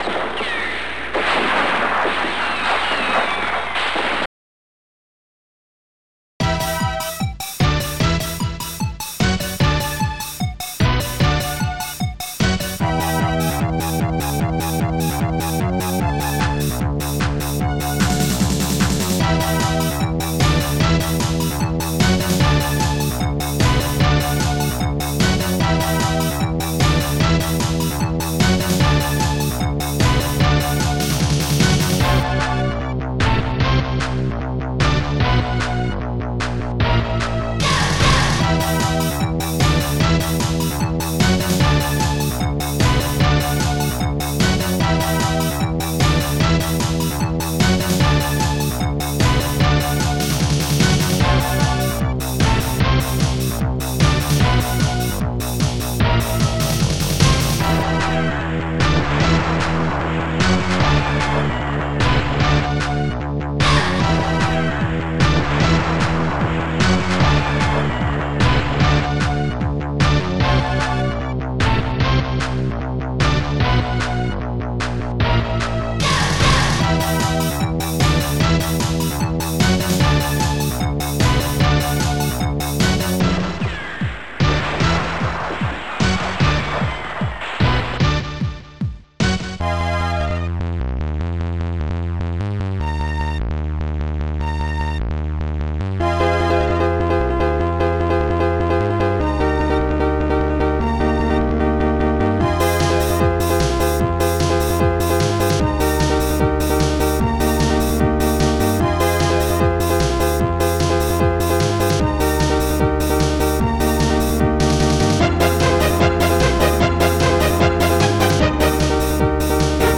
fanfare
exbells
strings
claps
gunshots
POLYSYNTH
PIANO